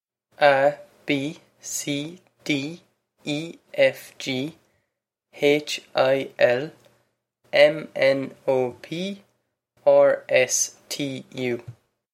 (a is pronounced like ah, not ay)
This is an approximate phonetic pronunciation of the phrase.